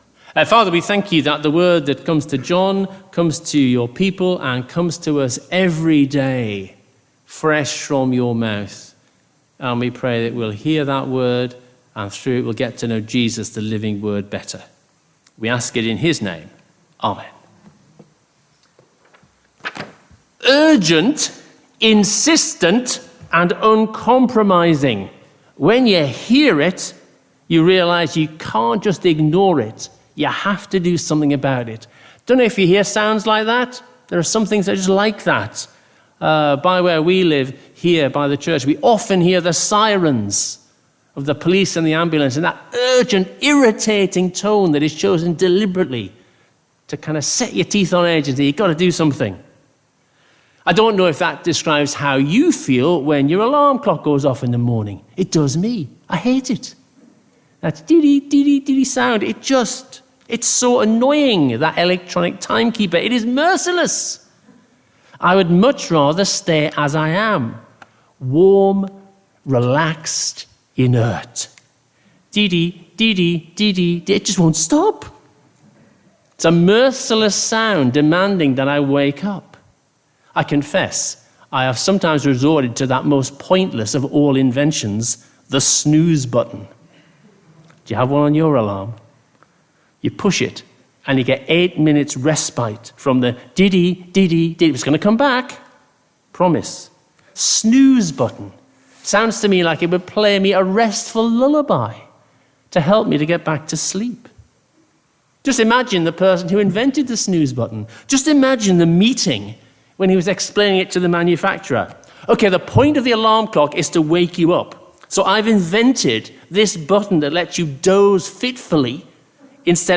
Sermons – Page 11 – St Marks Versailles